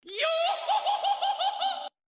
Download “luffy laugh” luffy-laugh.mp3 – Downloaded 7748 times – 212.39 KB